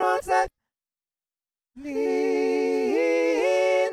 RvrsVoxShard.wav